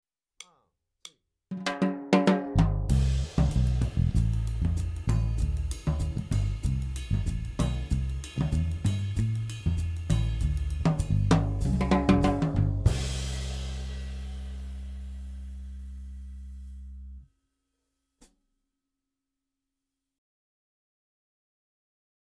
第3章 ウォーキングベース以外の伴奏
音源7:カリプソ